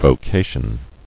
[vo·ca·tion]